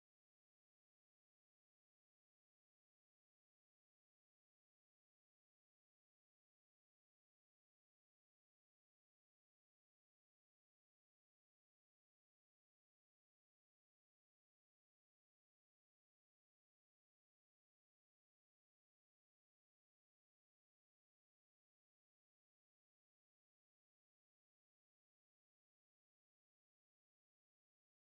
Close encounter with a gentle giant. An adult humpback maneuvered close to our fishing boat.